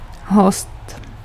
Ääntäminen
France: IPA: /kɔ̃.viv/